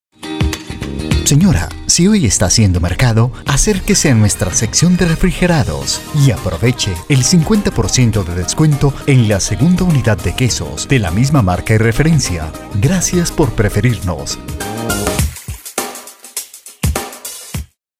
Voz joven, dinamica, también puede ser amable e institucional; puedo producir totalmente su spot comercial o simplemente grabar la voz en off
Sprechprobe: Sonstiges (Muttersprache):
young voice, dynamic, can also be kind and institutional I can fully produce your commercial spot or simply record the voiceover